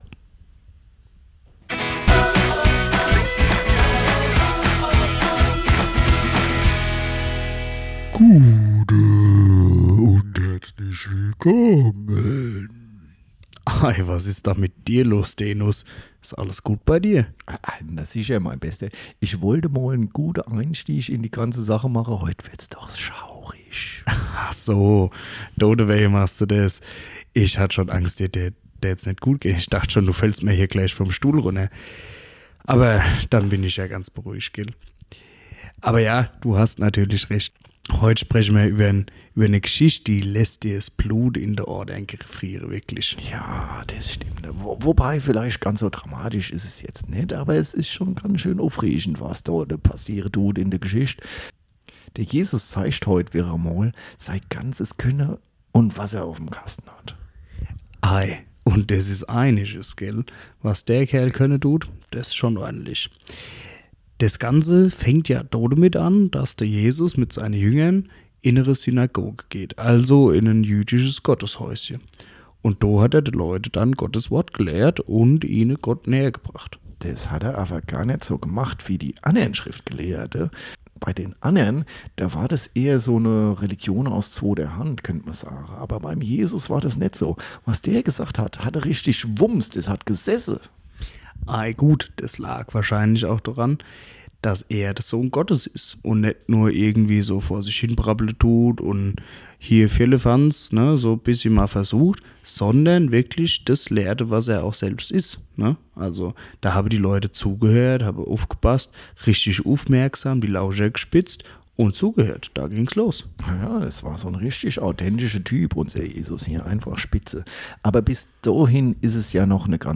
Auf hessisch durch das Maggus-Evangelium In Folge 5 zeigt Jesus,